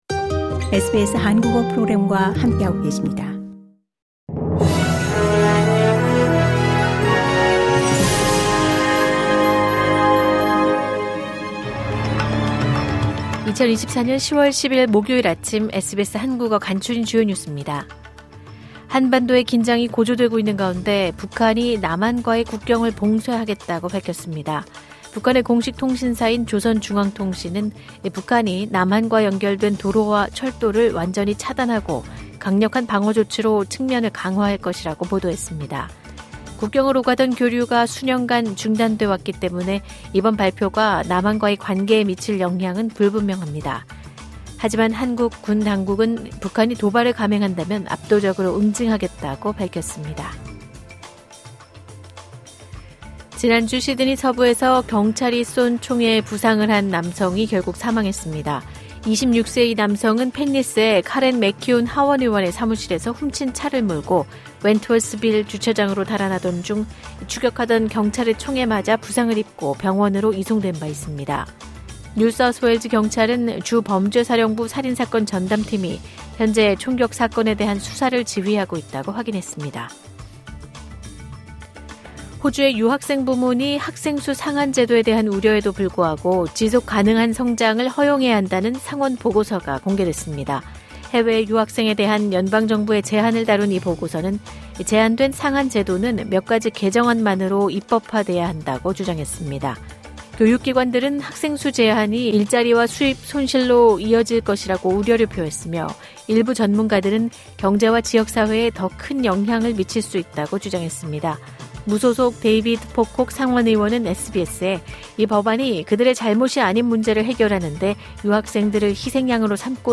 2024년 10월 10일 목요일 아침 SBS 한국어 간추린 주요 뉴스입니다.